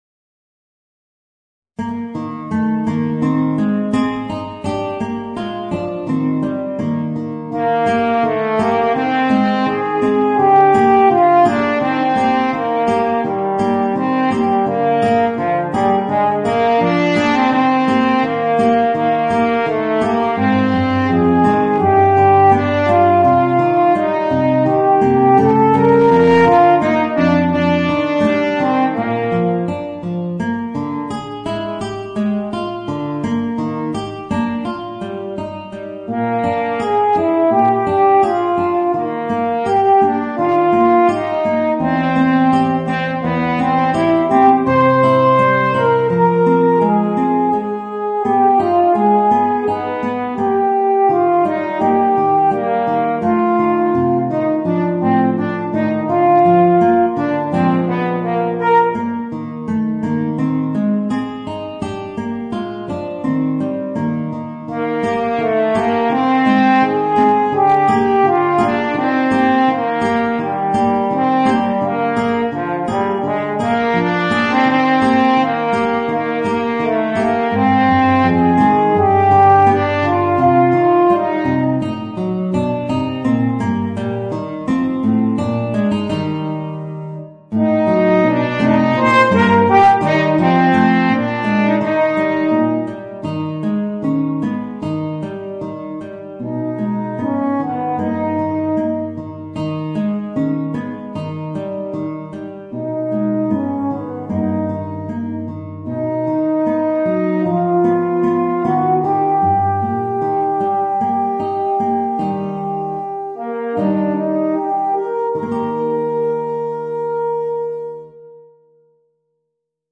Voicing: Horn and Guitar